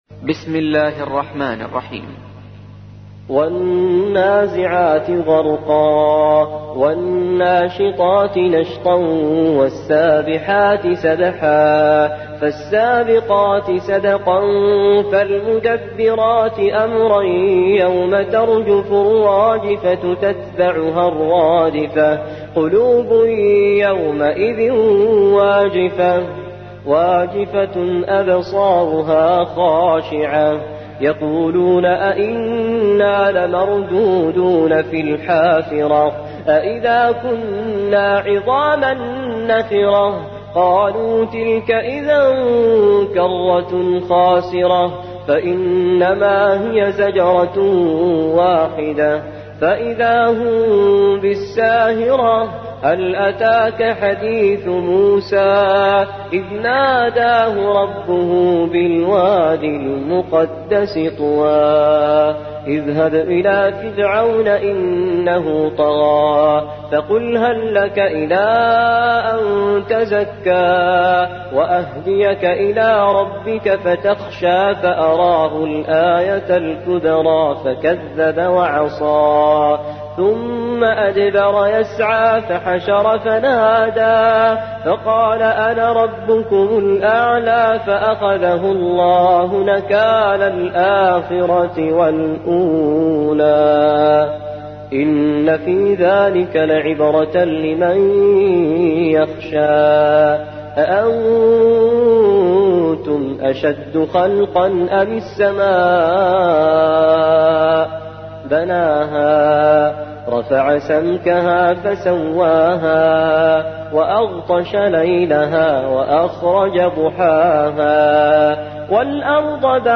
تحميل : 79. سورة النازعات / القارئ توفيق الصايغ / القرآن الكريم / موقع يا حسين